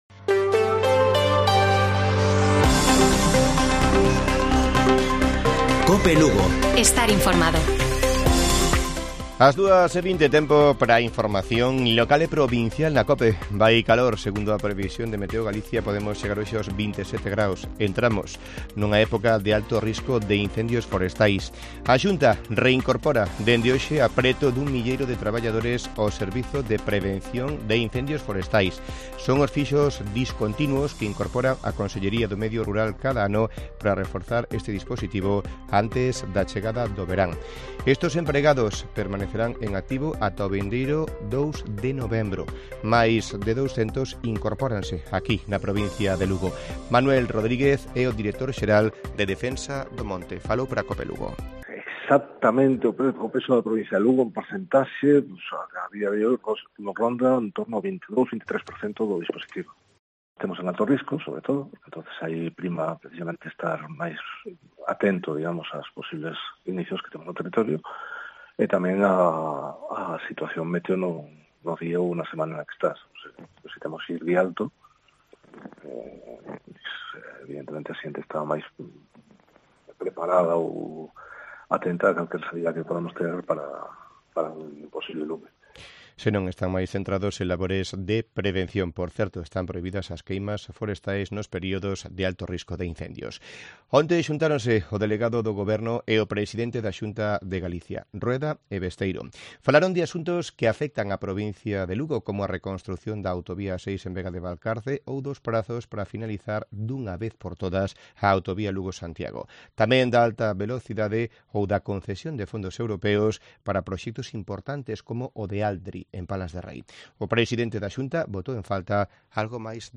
Informativo Mediodía de Cope Lugo. 3 DE MAYO. 14:20 horas